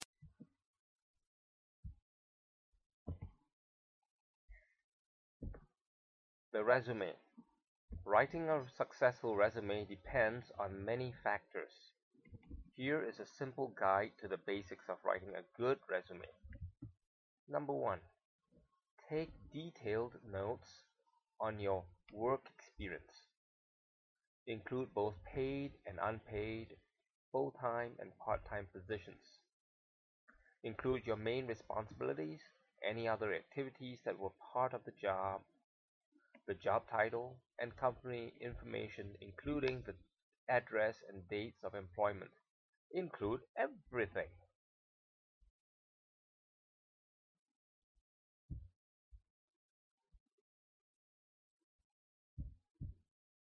listening and spelling exercise